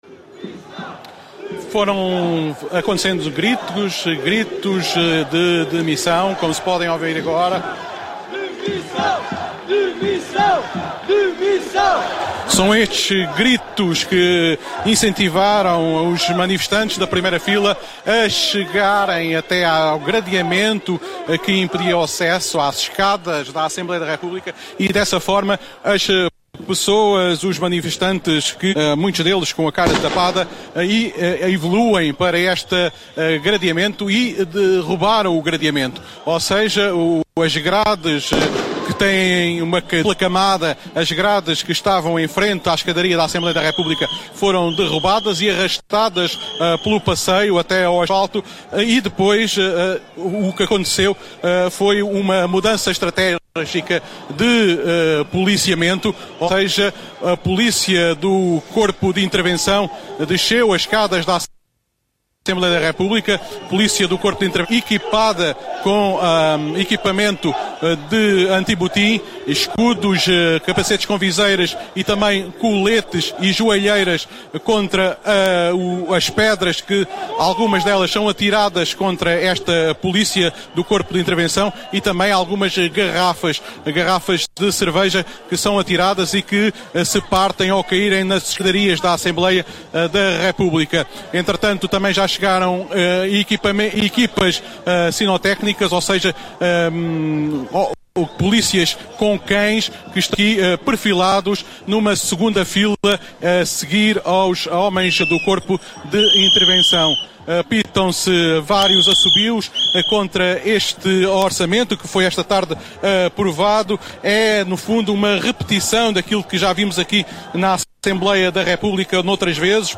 Protesto na Assembleia da Republica, no dia de votação na generalidade do Orçamento de Estado para 2013.